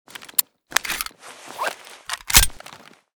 de_reload.ogg.bak